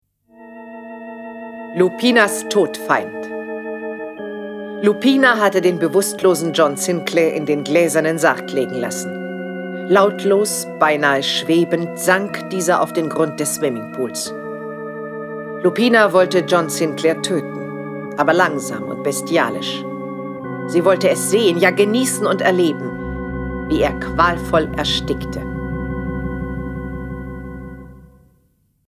Jason Dark (Autor) diverse (Sprecher) Audio-CD 2016 | 1.